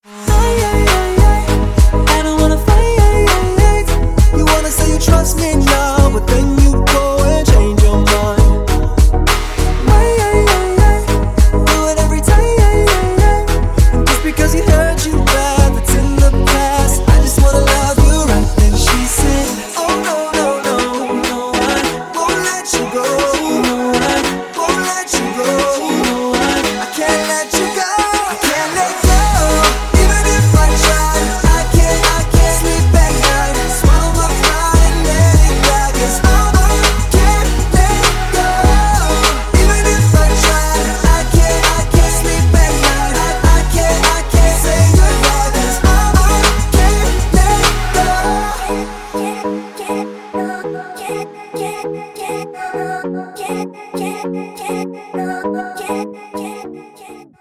• Качество: 320, Stereo
dance
RnB
vocal